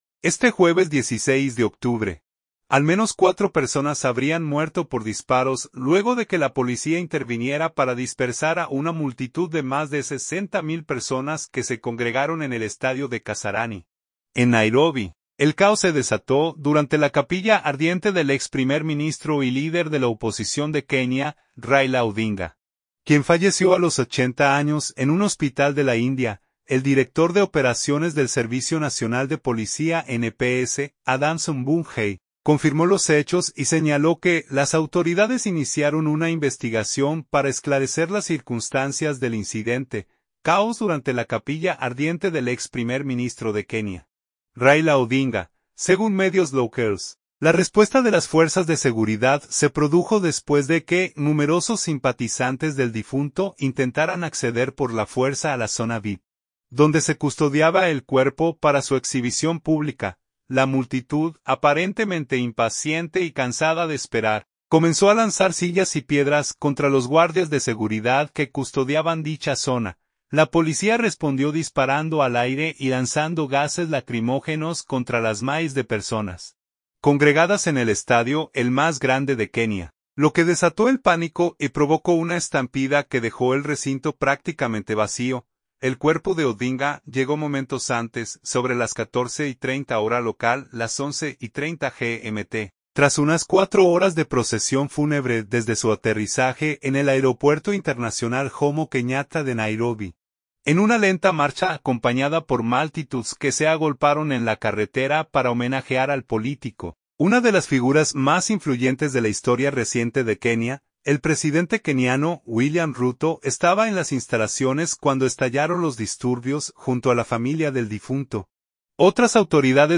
Caos durante la capilla ardiente del ex primer ministro de Kenia, Raila Odinga